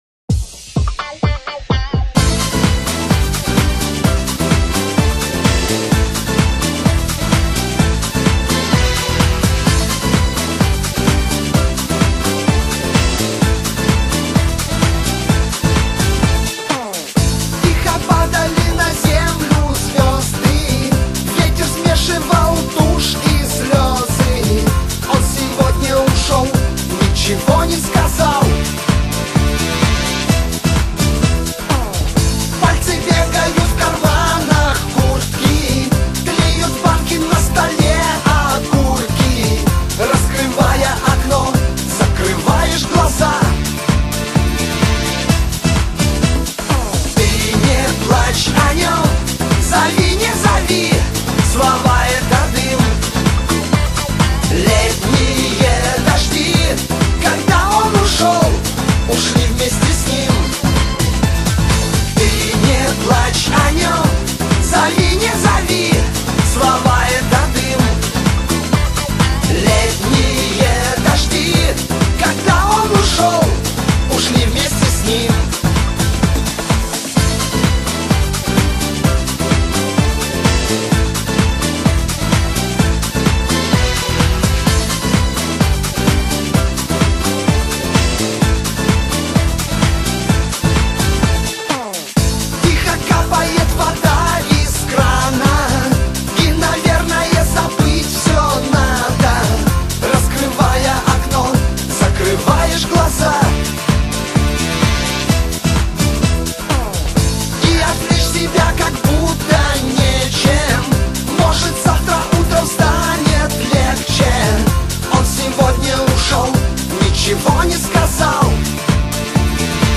pop MIX